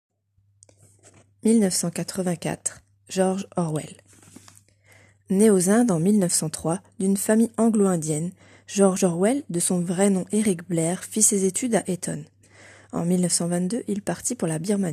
Lecture du Roman 1984